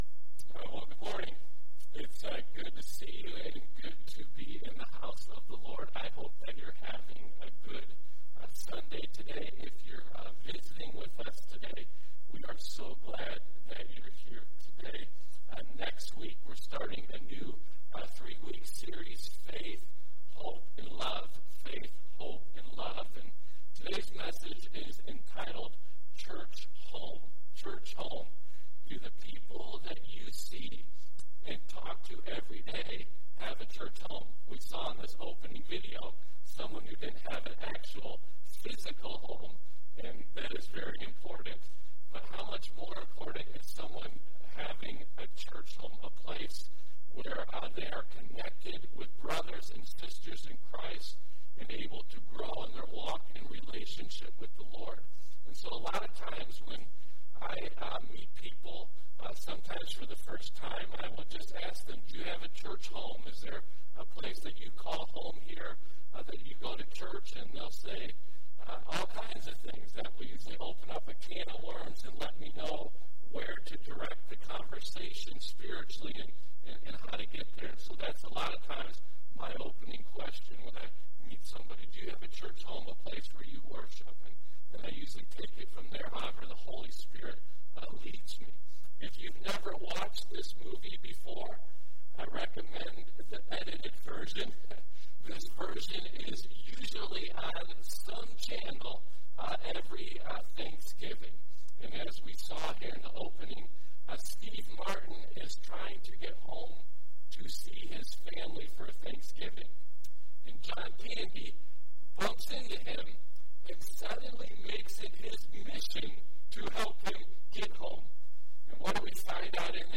Stone Ridge Community Church Sermon Audio Library